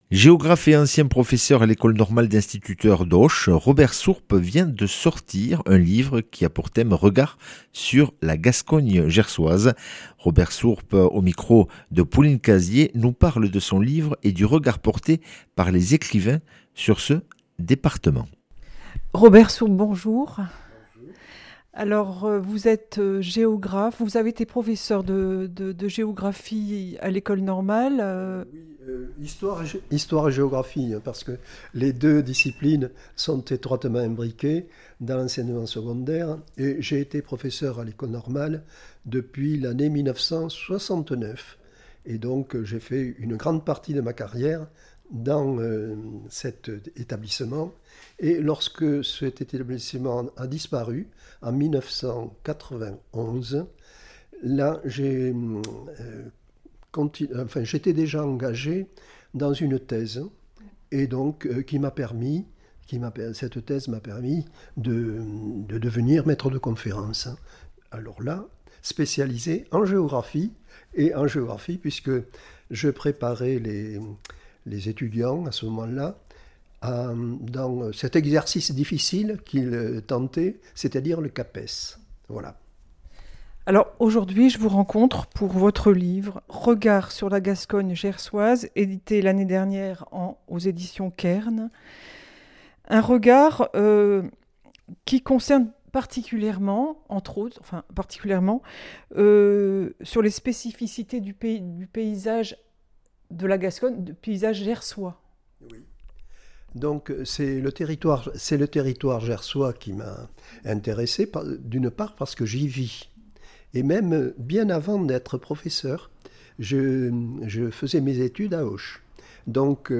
Interview et reportage du 03 sept.